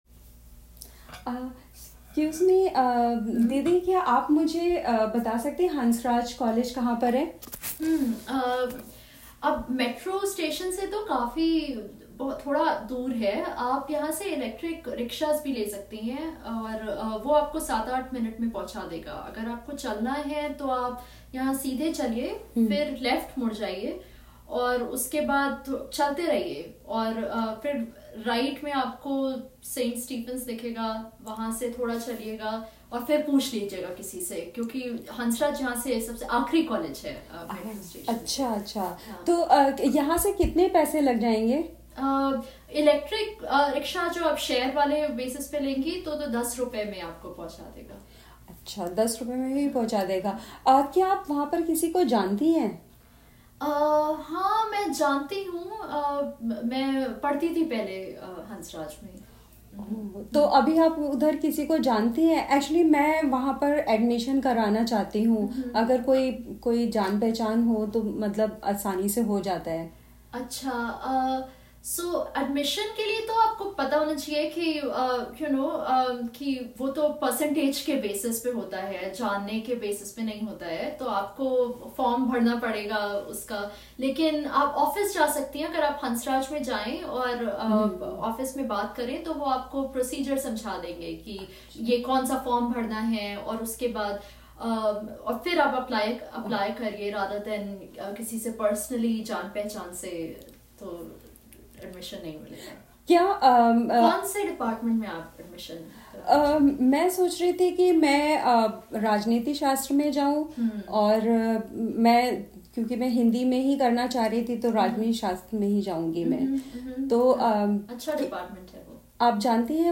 Role-Play: Asking for Directions and Long Chatting – दिमाग़ का दही
1. Where does the tourist lady want to go?
2. What is the best transportation suggested by the local lady?